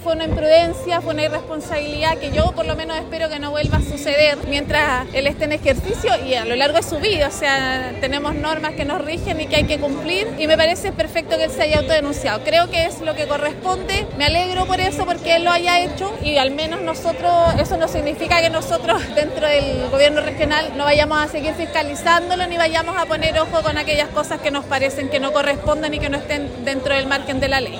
Por su parte, la consejera Yasna Jaramillo, del Partido Republicano, señaló que velarán por el buen uso de los recursos públicos y que pese al error de Giacaman, su autodenuncia fue lo correcto.